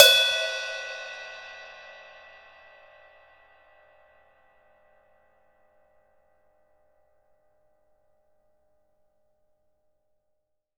Index of /90_sSampleCDs/Sampleheads - New York City Drumworks VOL-1/Partition A/KD RIDES